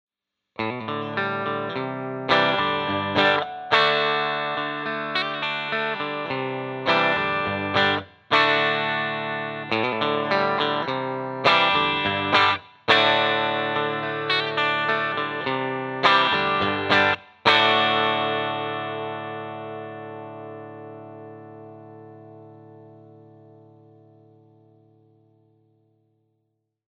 65T (1965 Tele lead type) alone
65T  bridge.mp3